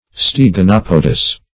Meaning of steganopodous. steganopodous synonyms, pronunciation, spelling and more from Free Dictionary.
Search Result for " steganopodous" : The Collaborative International Dictionary of English v.0.48: Steganopodous \Steg`a*nop"o*dous\, a. (Zool.) Having all four toes webbed together.